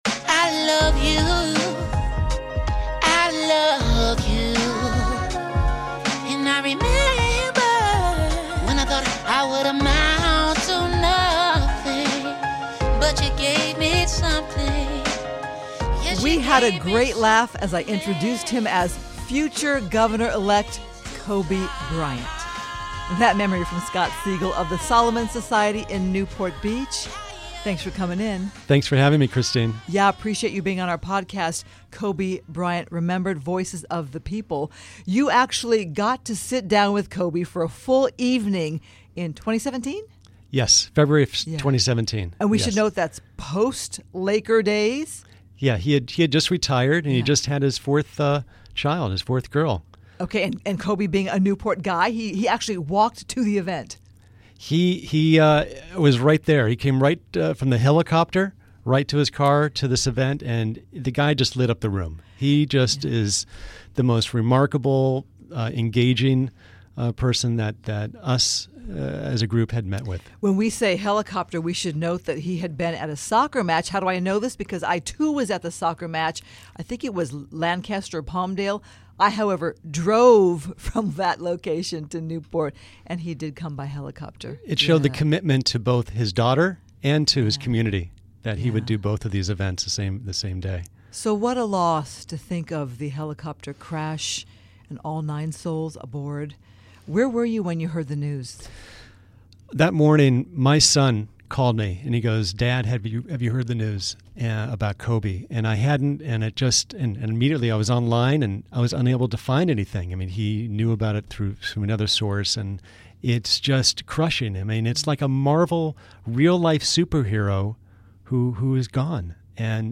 With it being the one month anniversary this week of the passing of Kobe Bryant, a bishop shares his thoughts on moving forward after the memorial service.